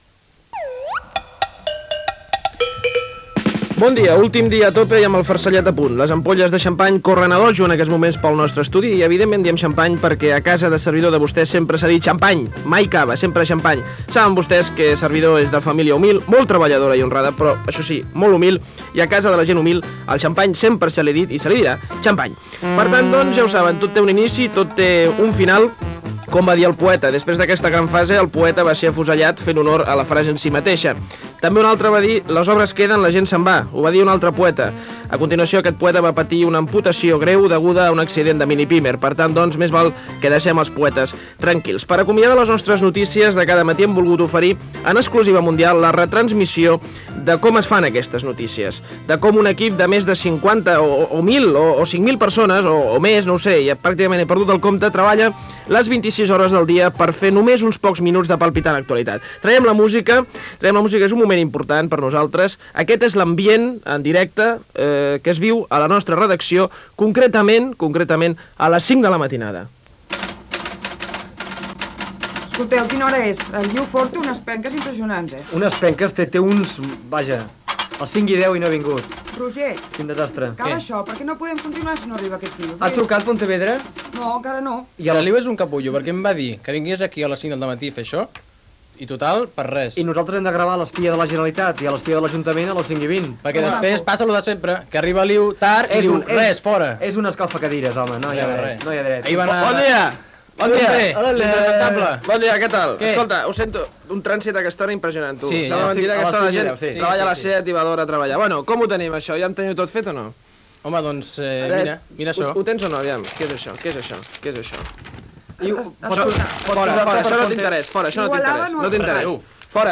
Espai humorístic: ambient de treball a la redacció del programa
Info-entreteniment
FM